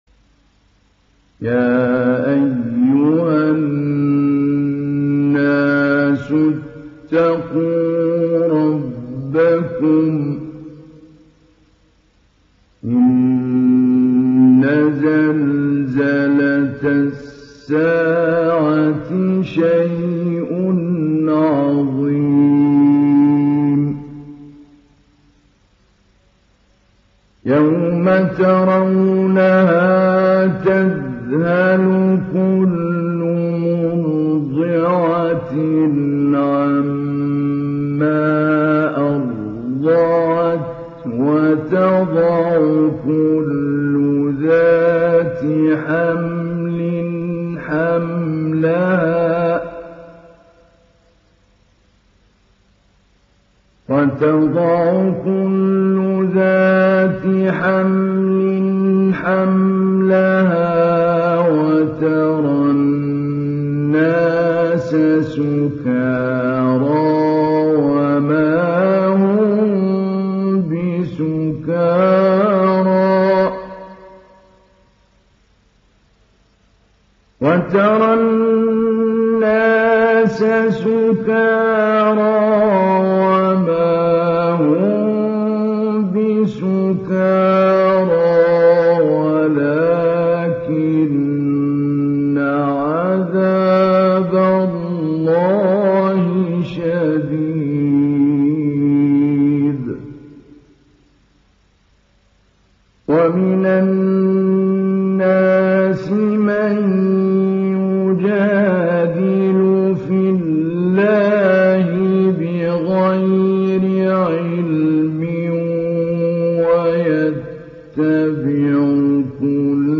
ডাউনলোড সূরা আল-হাজ্জ Mahmoud Ali Albanna Mujawwad